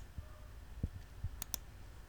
Klick
Das Klicken einer Computermaus ist ein mittlerweile alltäglich gewordenes Geräusch, das aber selten bewusst wahrgenommen wird (genauso wie das Geräusch eines Scrollrades).